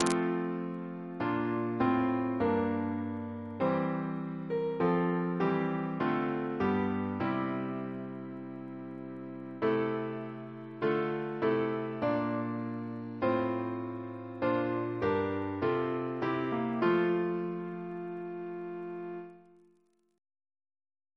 Double chant in F Composer: Edward Cutler (1831-1916), Organist of Whitchurch, Edgware Reference psalters: ACB: 183; RSCM: 38